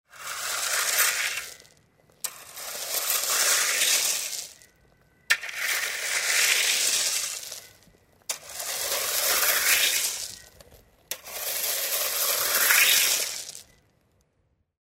Граблями сгребание гравия